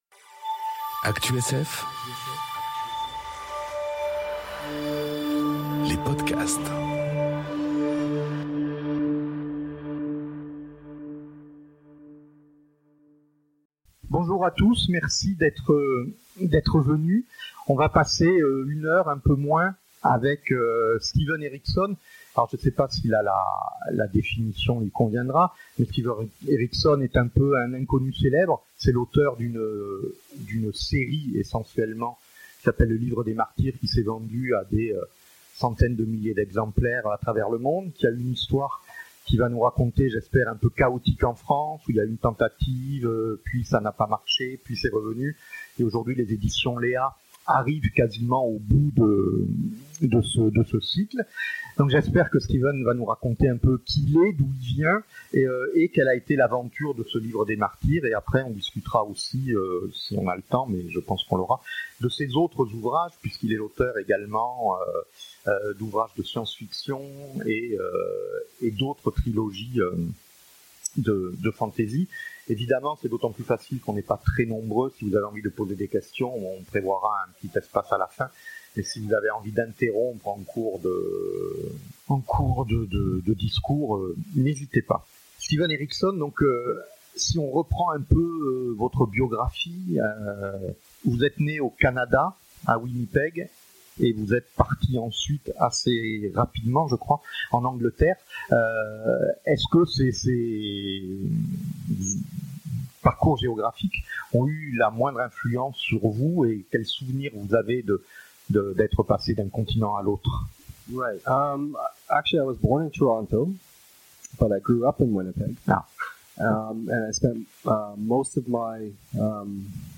Imaginales 2022 - Le Grand entretien : Steven Erikson
A l'occasion des Imaginales 2022, on vous propose de (ré)écoutez le Grand entretien de Steven Erikson.